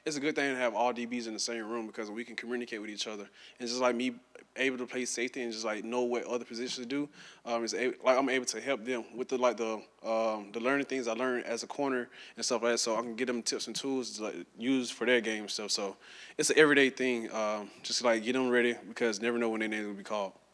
In a news conference Wednesday, the senior spoke at length about the Gators getting back on track following last week’s blowout loss at Texas.